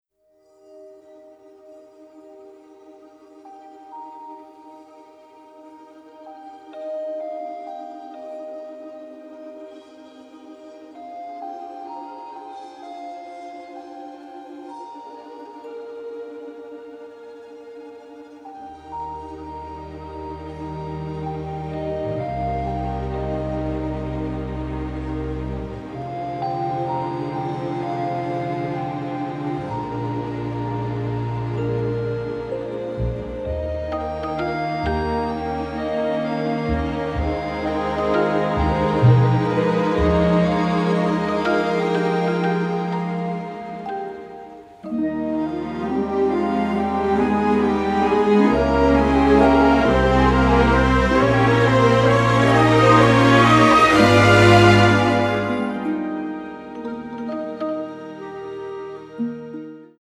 The music was recorded in magnificent sound in London